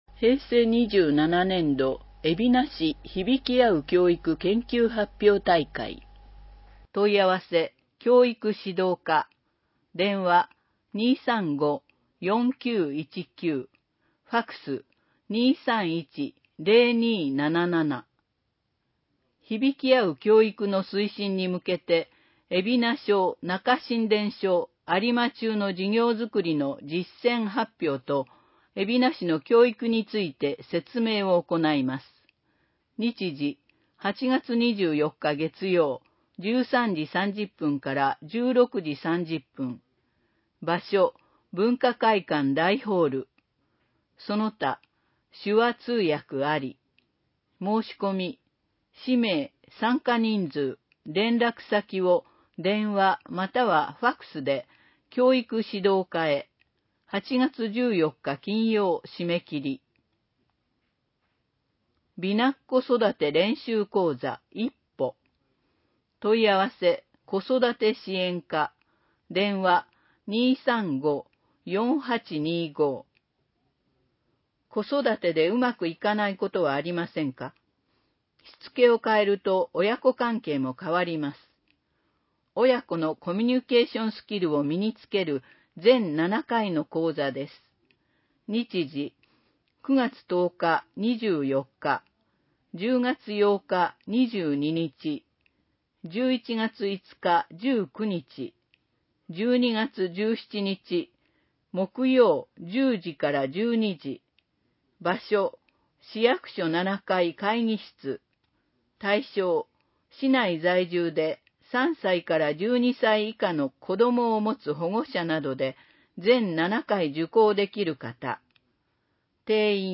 広報えびな 平成27年8月1日号（電子ブック） （外部リンク） PDF・音声版 ※音声版は、音声訳ボランティア「矢ぐるまの会」の協力により、同会が視覚障がい者の方のために作成したものを登載しています。